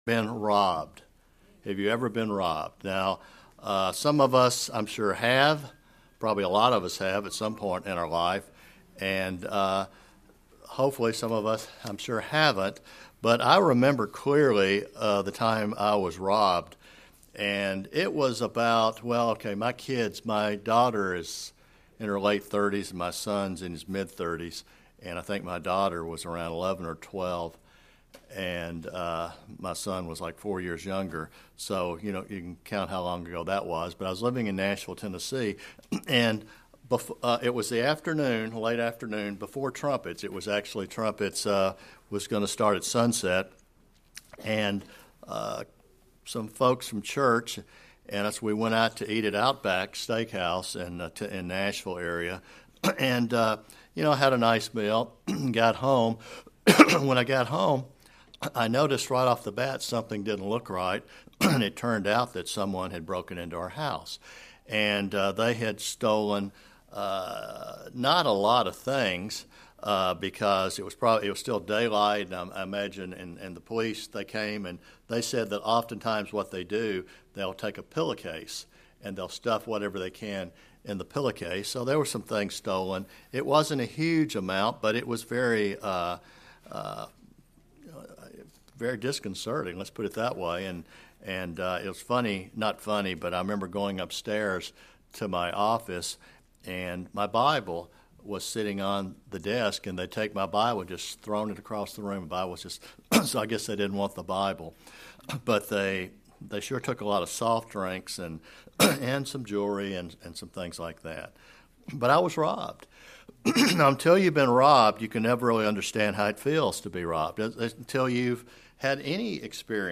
So can we learn with certainty the truth about the resurrection. Follow along in this Sermon the truth of the resurrection and you can discover it for yourself
Given in St. Petersburg, FL